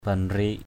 /ba-nri:ʔ/ (cv.) binrik b{\n{K , banik bn{K